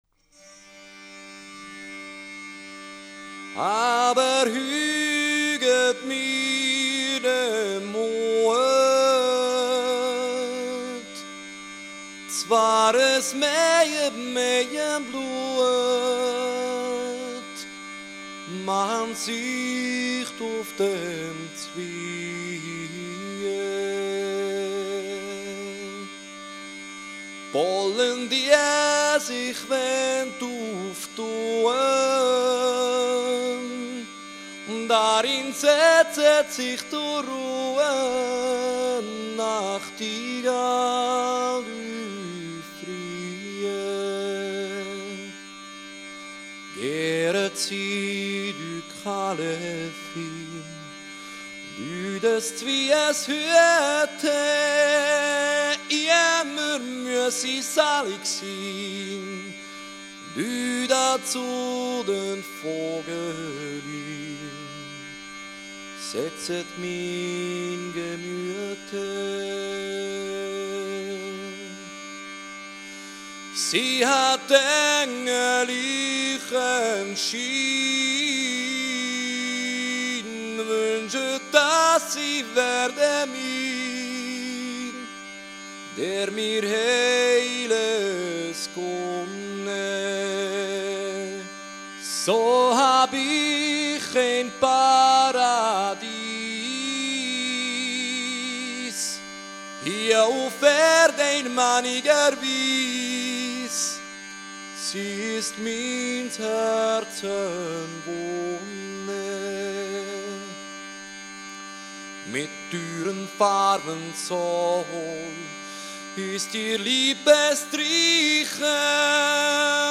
Minnelieder aus dem Hochmittelalter